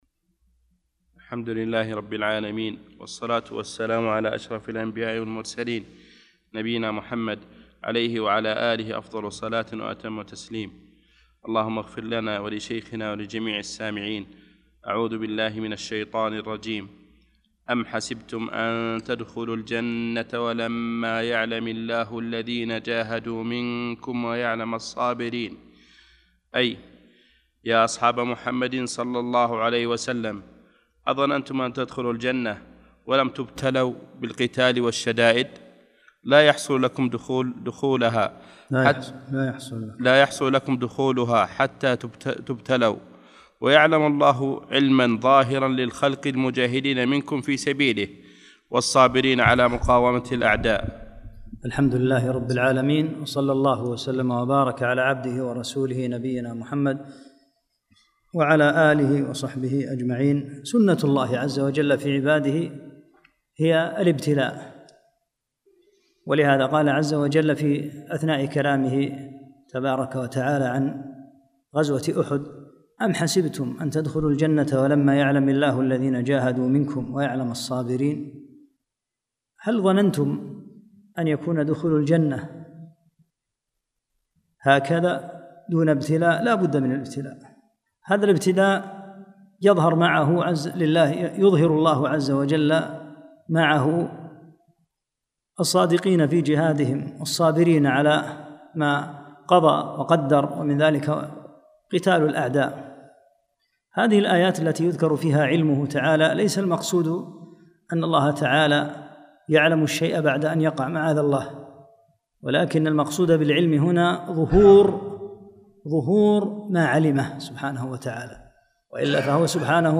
16 - الدرس السادس عشر